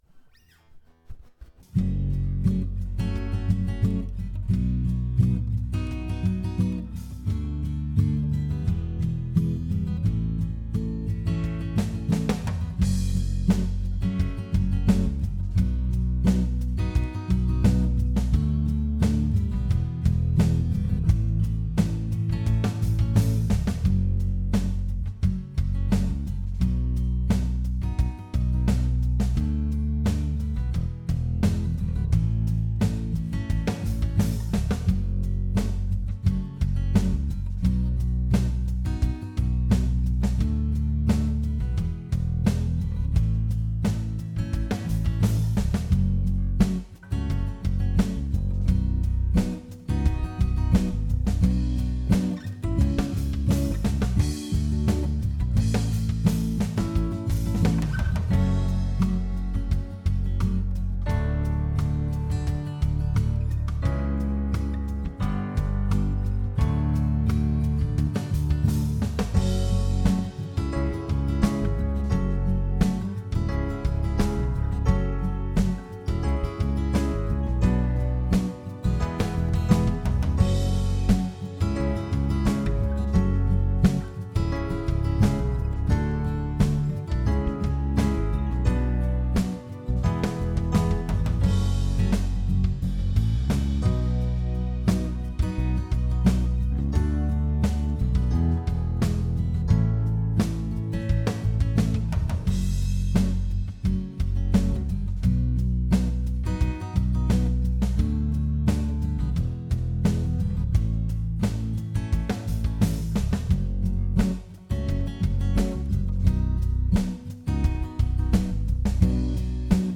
Jam Track
Jam track inspired by